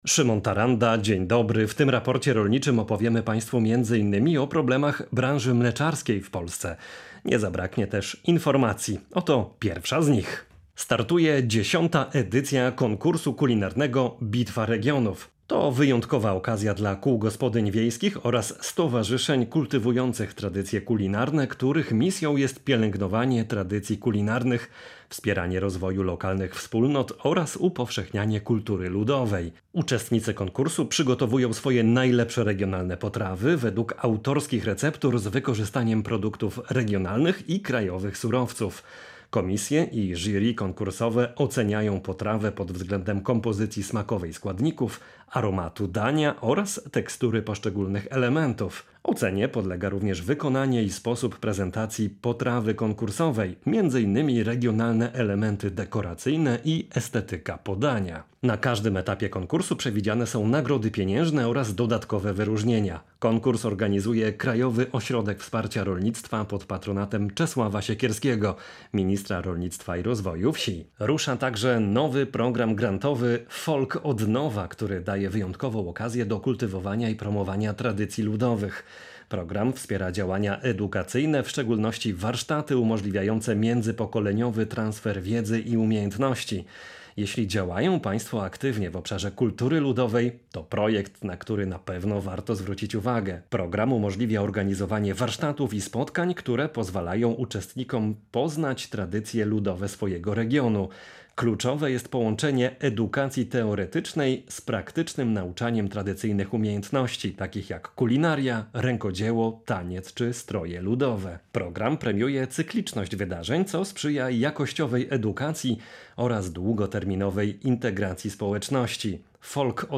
Raport rolniczy • Wyzwania rynku mleczarskiego